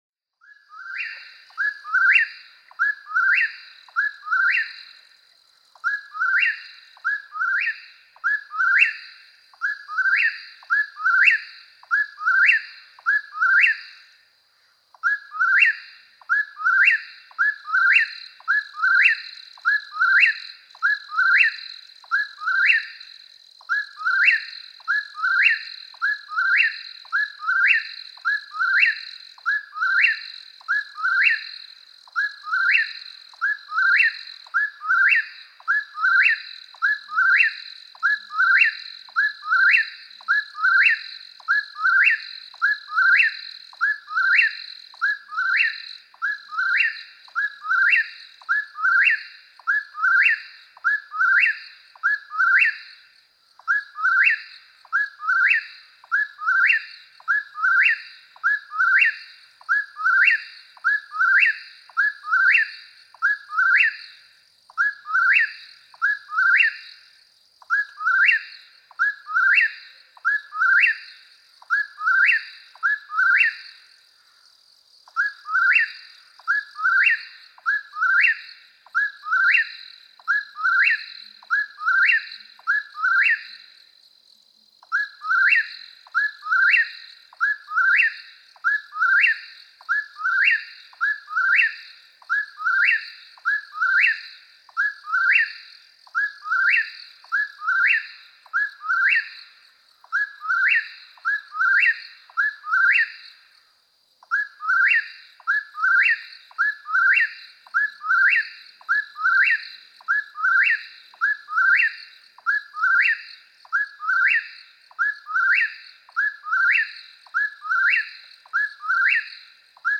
♫80. And lots more whip-poor-wills, as is their nature. Just imagine keeping this up all night long!
Mammoth Cave National Park, Kentucky.
080_Eastern_Whip-poor-will.mp3